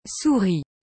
Exemples : Dans les mots « lait », « souris », « yeux » et « accord », la consonne finale est muette à l’oral.